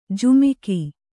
♪ jumiki